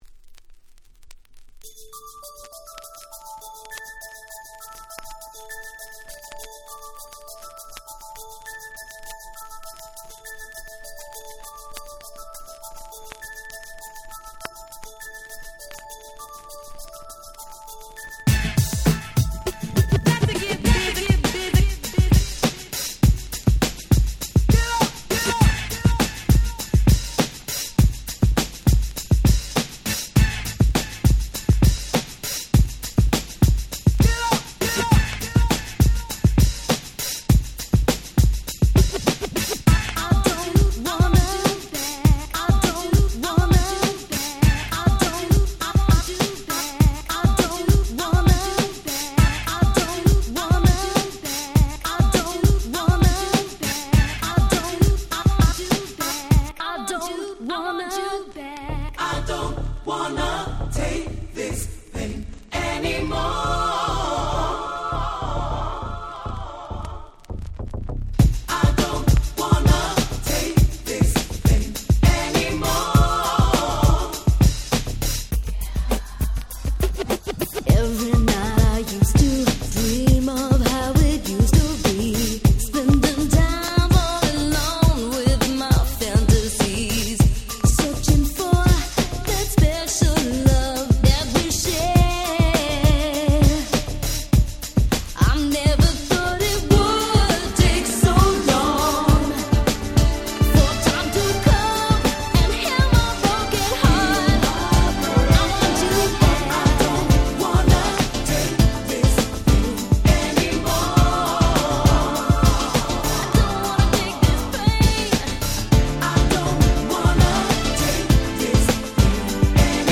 91' Nice UK R&B !!